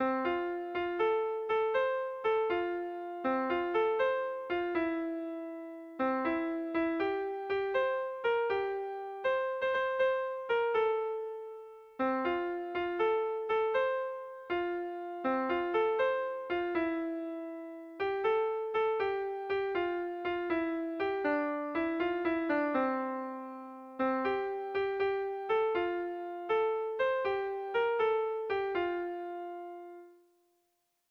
Bertso melodies - View details   To know more about this section
Sentimenduzkoa
Hamarreko txikia (hg) / Bost puntuko txikia (ip)
ABADE